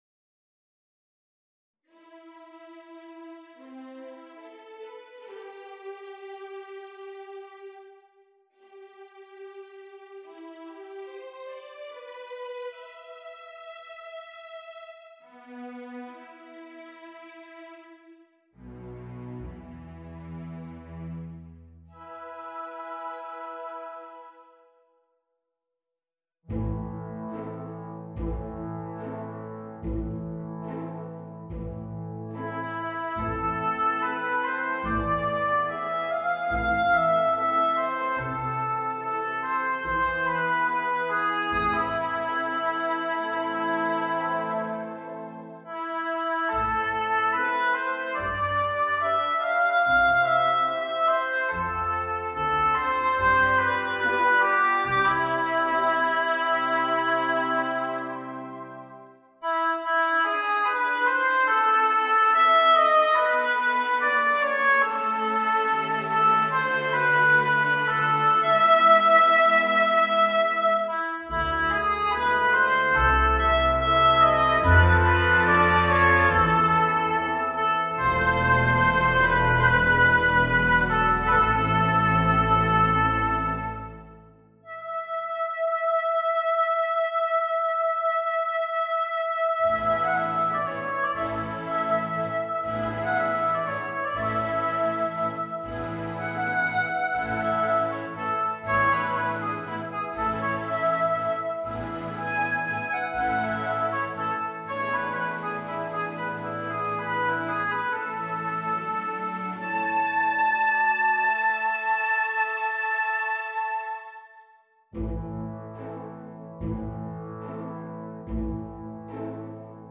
Voicing: Bassoon and Orchestra